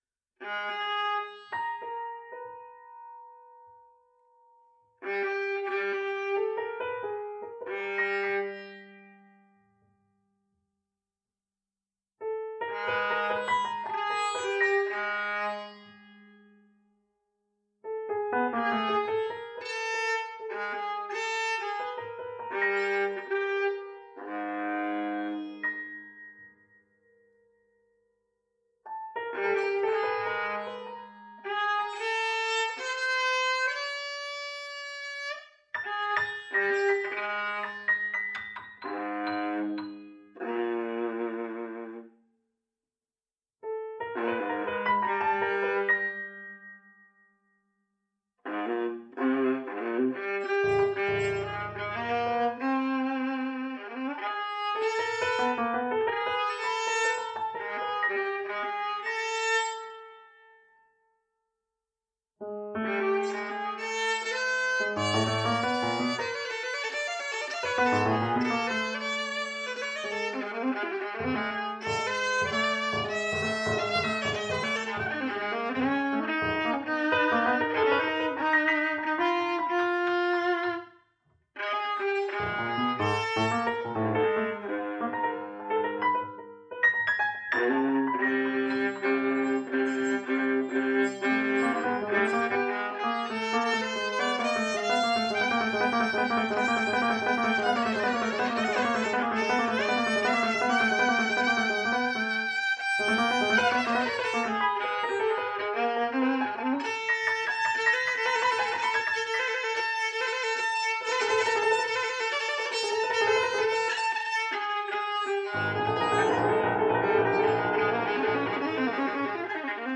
improvised music for violins and piano